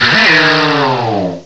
cry_not_hakamo_o.aif